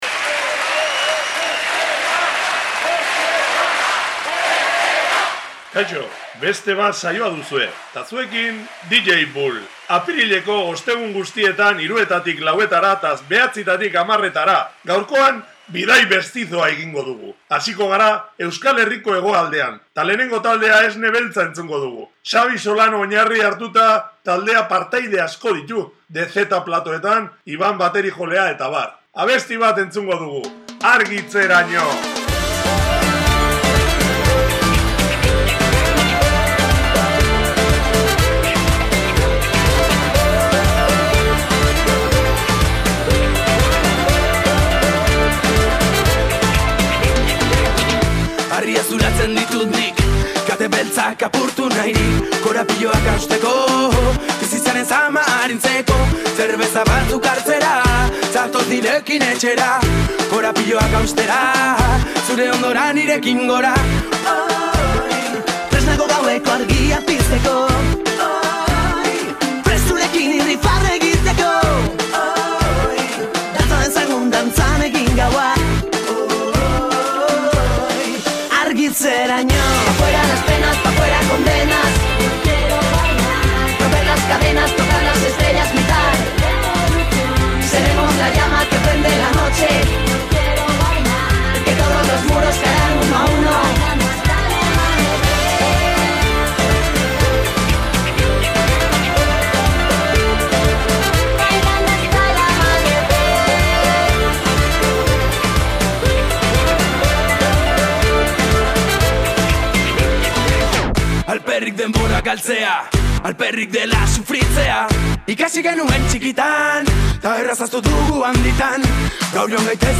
Bertako musikarekin ere egin daiteke saio mestizo bat.